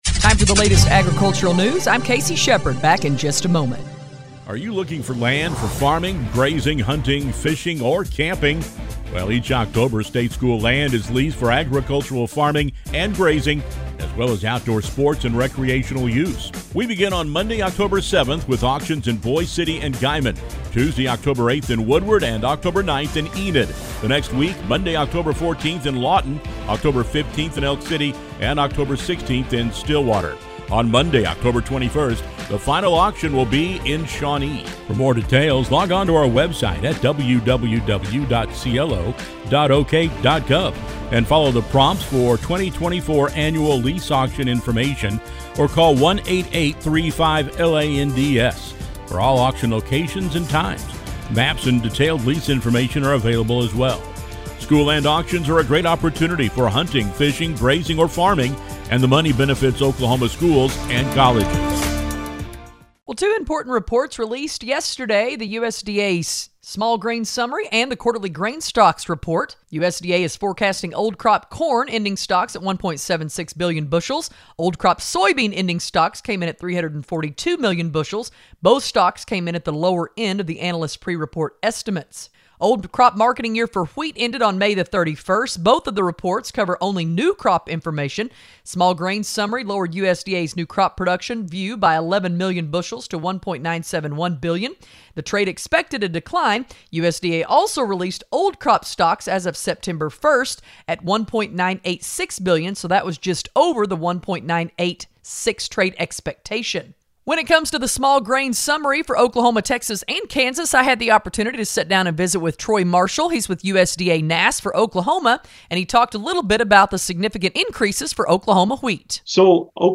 We invite you to listen to us on great radio stations across the region on the Radio Oklahoma Ag Network weekdays-